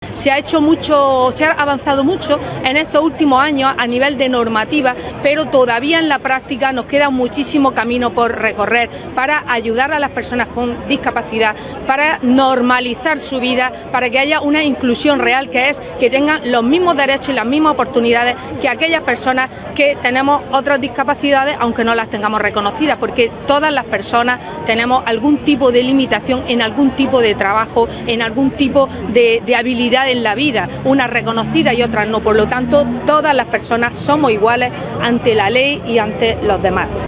El acto se ha desarrollado en la Avenida Federico García Lorca de la capital y ha contado con el conjunto de entidades de Almería que representan a las personas con discapacidad.